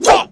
wrench_alt_fire4.wav